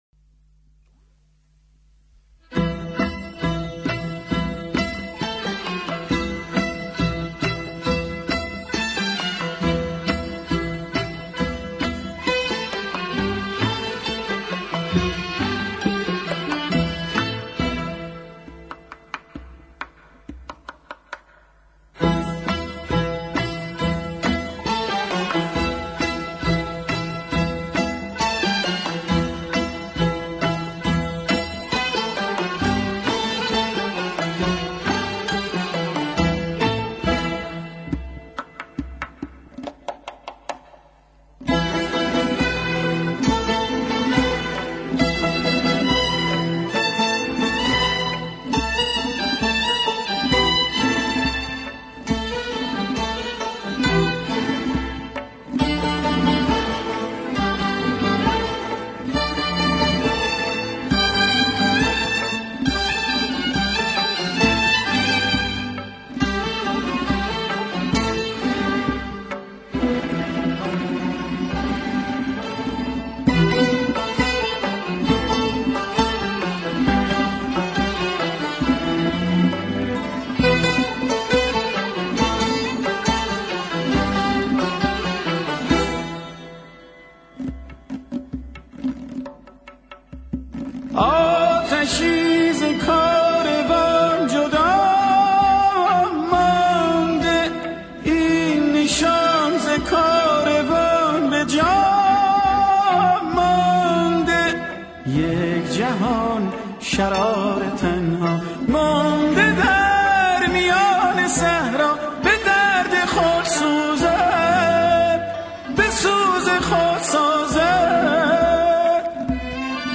اهنگ سنتی